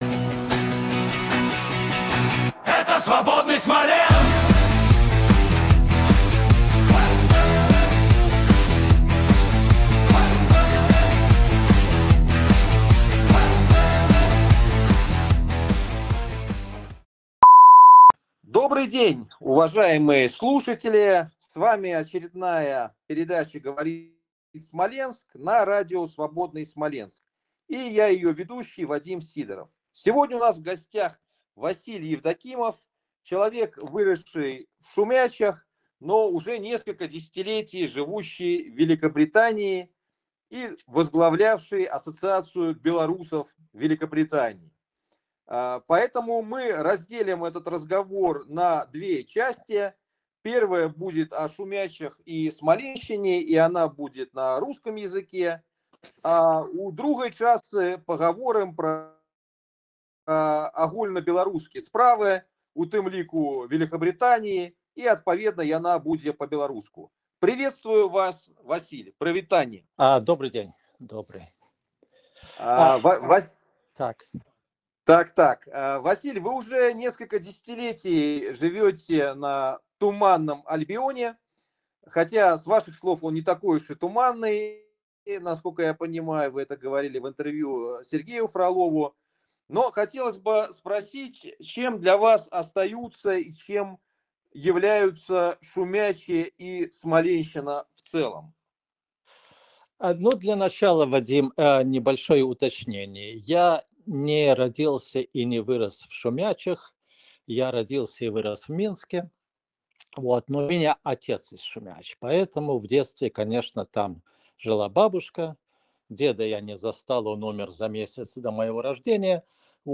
(беседа шла сперва на русском языке, а потом перешла на беларуский)